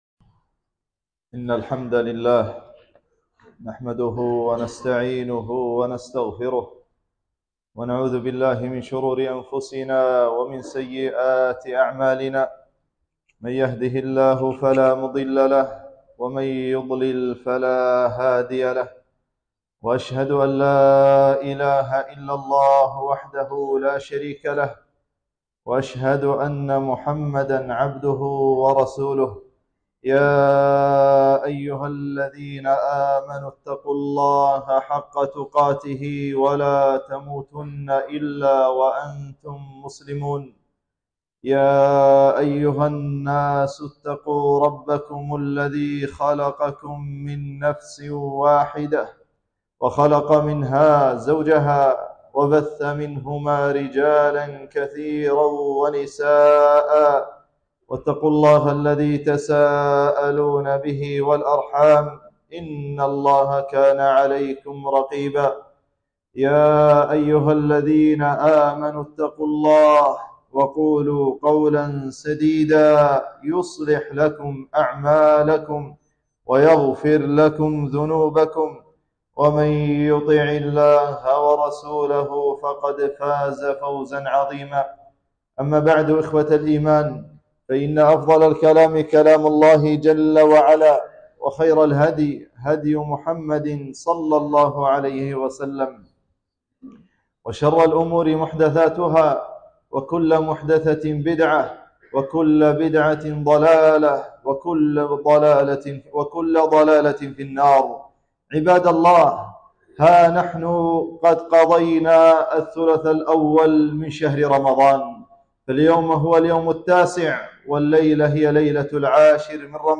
خطبة - الاجتهاد في رمضان